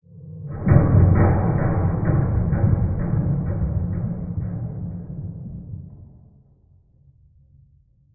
Minecraft Version Minecraft Version snapshot Latest Release | Latest Snapshot snapshot / assets / minecraft / sounds / ambient / cave / cave16.ogg Compare With Compare With Latest Release | Latest Snapshot
cave16.ogg